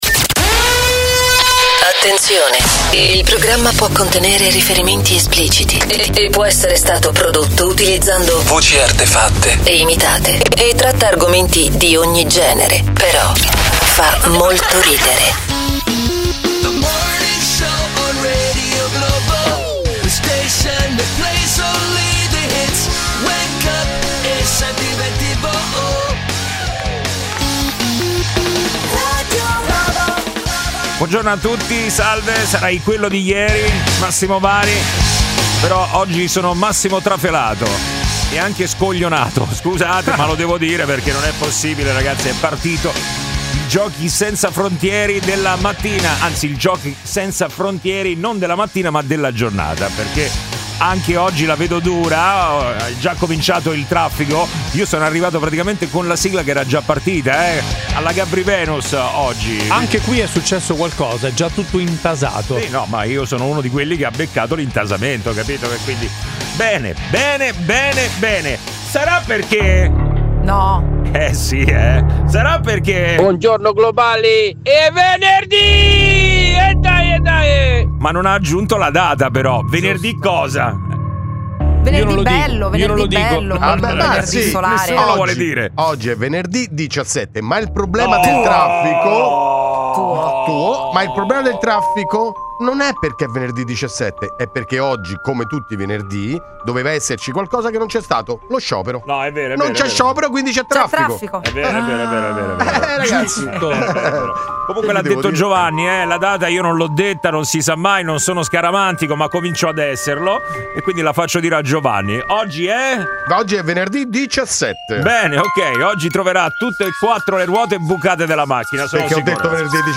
Umorismo, attualità, aggiornamenti sul traffico in tempo reale e l’immancabile contributo degli ascoltatori di Radio Globo, protagonisti con telefonate in diretta e note vocali da Whatsapp.